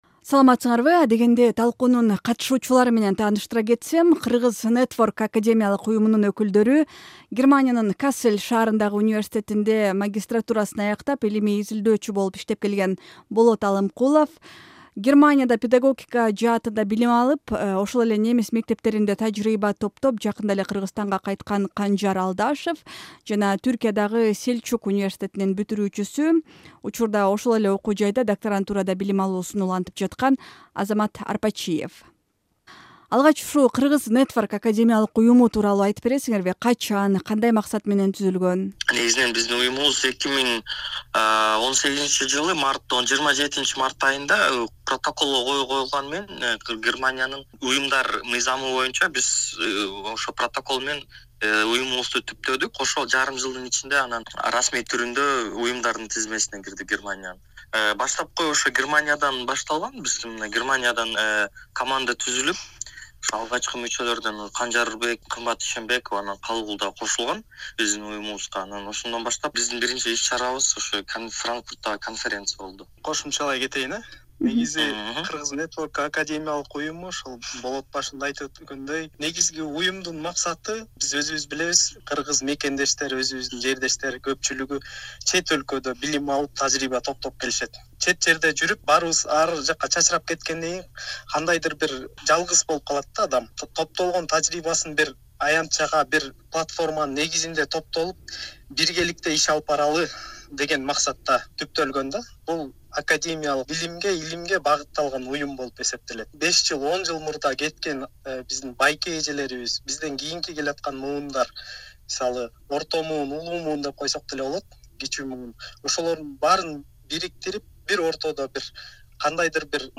"Арай көз чарай" талкуусунда чет жакта билим алып, иштеп жаткан мекендештерибиз өз өлкөсүнө кандай салым кошо алат? Кыргызстан кантип акыл эмгеги менен алдыга жылат? деген суроолорго жооп издейбиз.